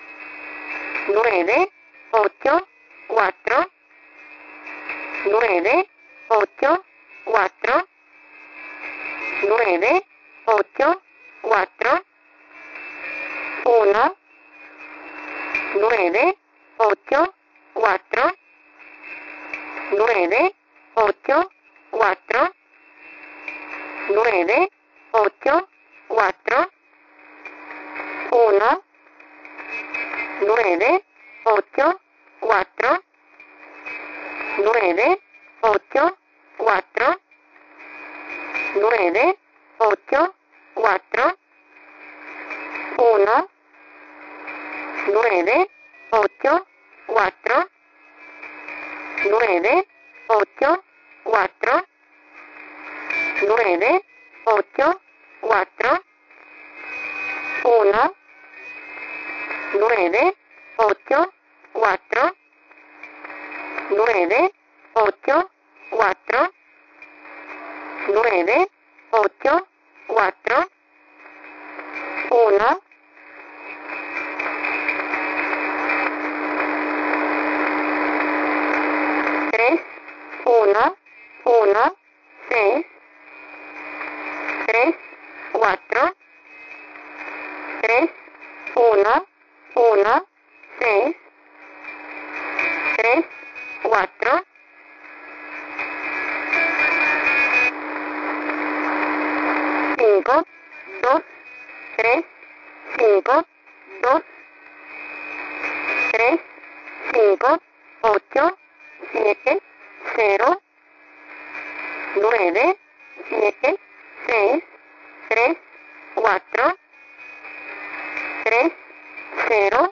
Mode: USB + Carrier